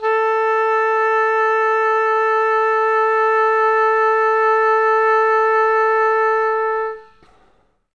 Index of /90_sSampleCDs/Best Service ProSamples vol.51 - Classic Orchestra 2 [AIFF, EXS24, HALion, WAV] 1CD/PS-51 AIFF AOE/Oboe